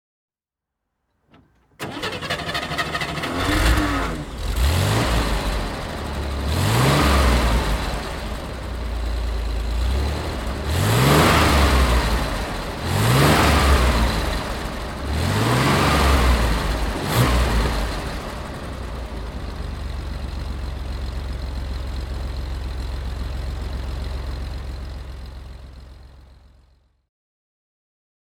It has an air-cooled six-cylinder boxer engine in the rear, four independent wheel suspensions and was built to be particularly light, but it is not a Porsche, it is a Chevrolet.
Chevrolet Corvair Monza Convertible (1962) - Starten und Leerlauf
Chevrolet_Corvair_Monza_1962.mp3